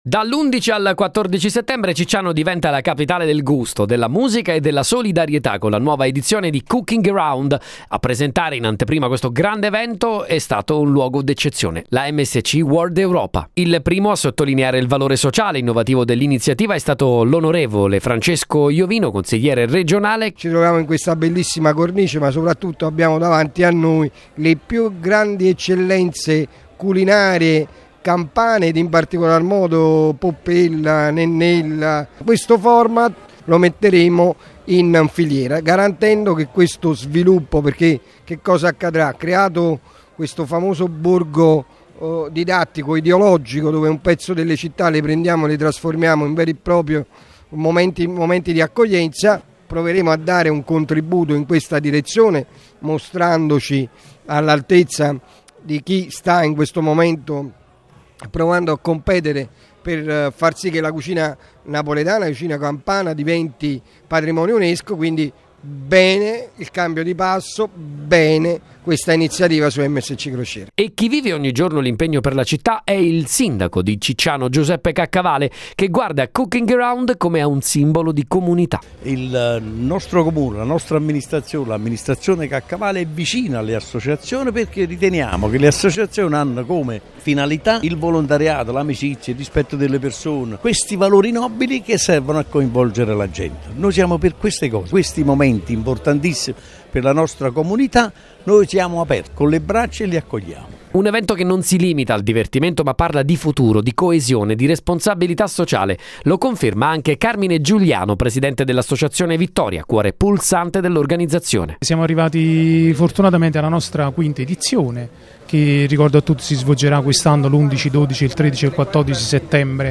servizio-giornalistico-cooking-around-1.mp3